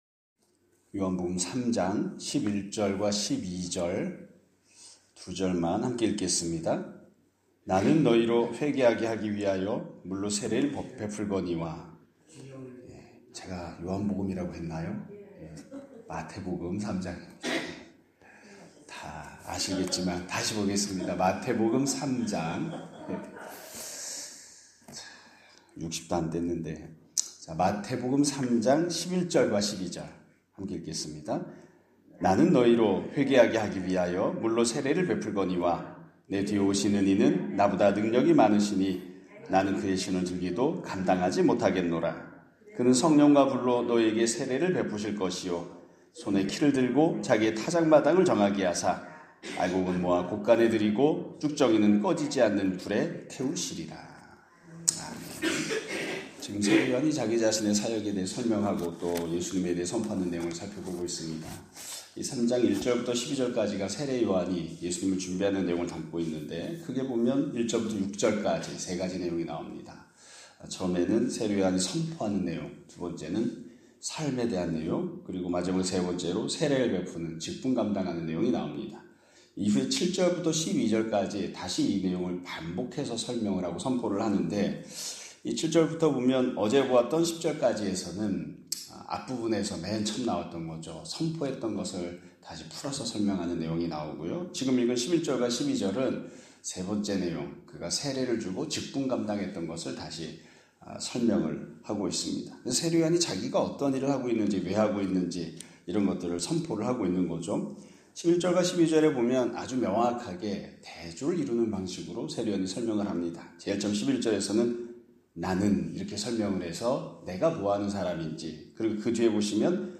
2025년 4월 15일(화요일) <아침예배> 설교입니다.